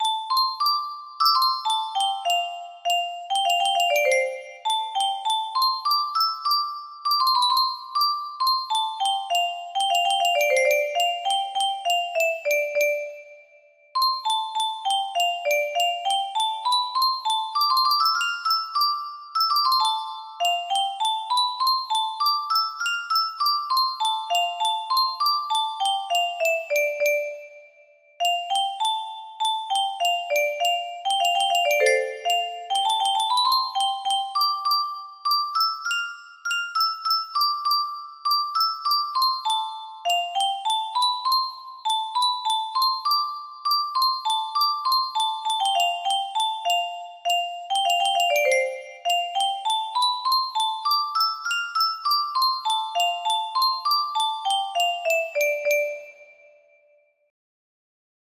Fanny Dillon-- O'Carolan music box melody